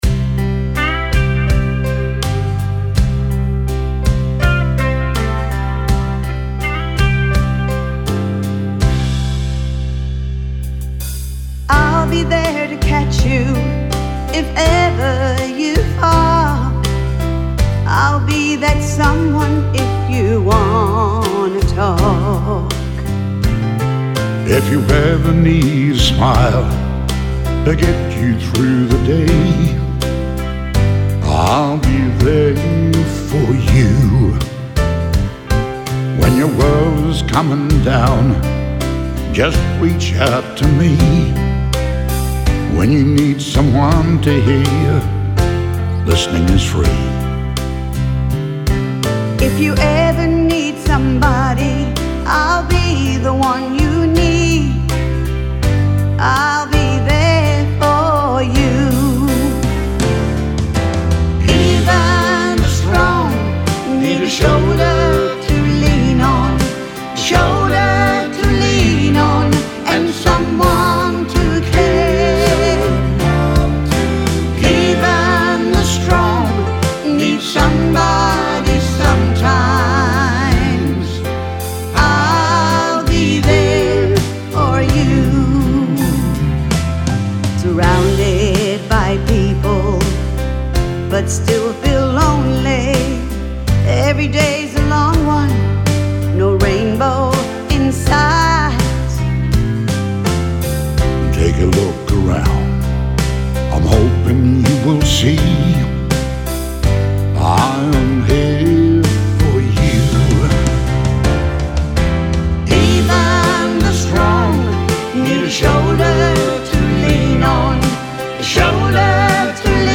traditional country upbeat flavour
duet